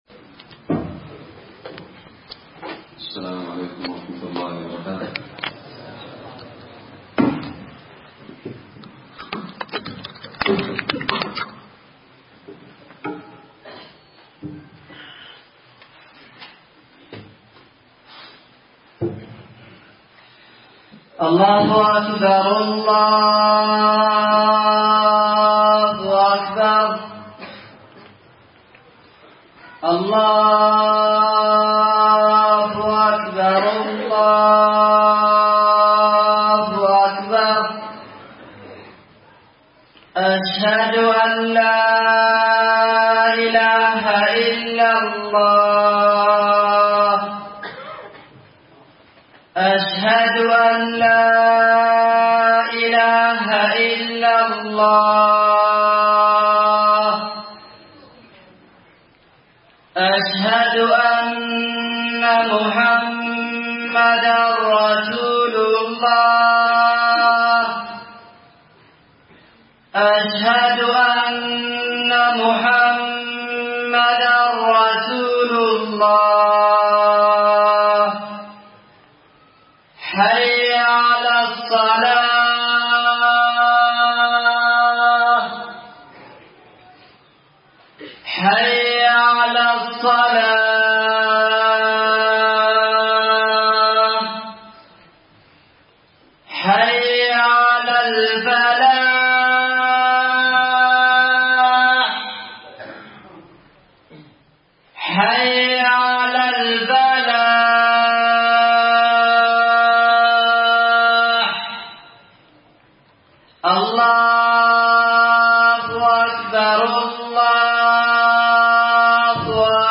خطب الجمعة
ألقيت بدار الحديث السلفية للعلوم الشرعية بالضالع في 9 جمادى الأولى 1439هــ